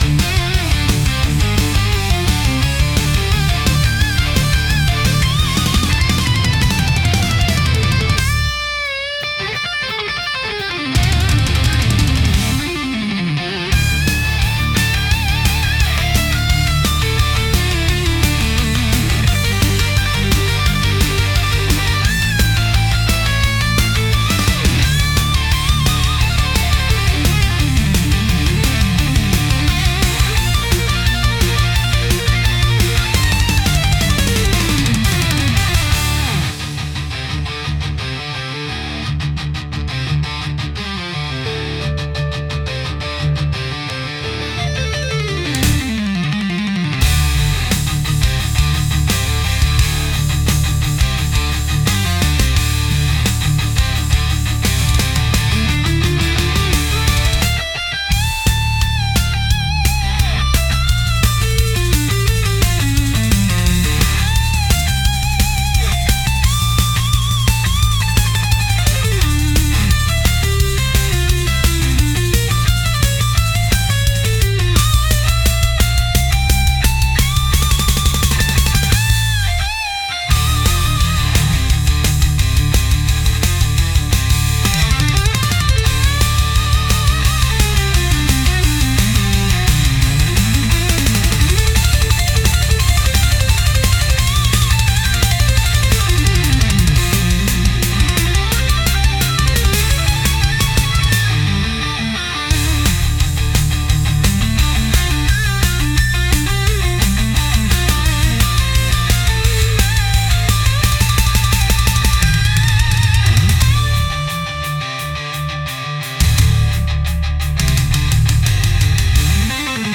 Genre: Rock Mood: Aggressive Editor's Choice